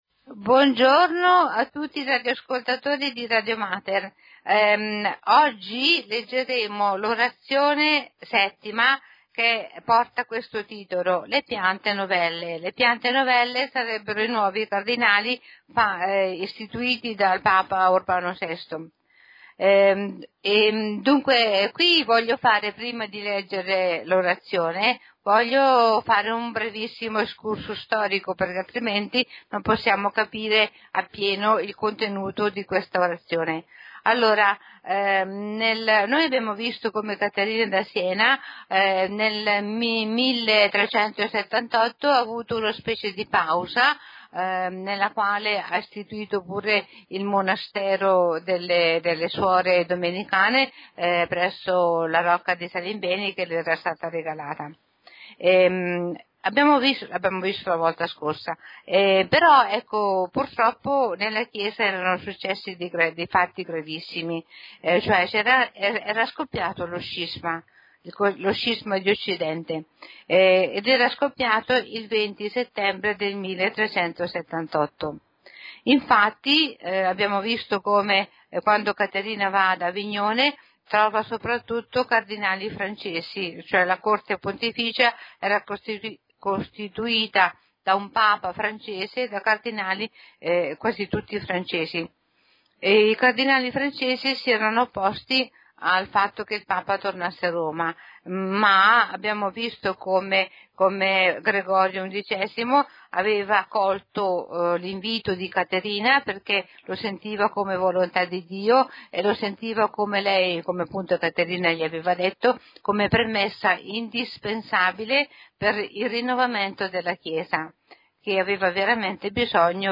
Lettura delle Orazioni e commento di S. Caterina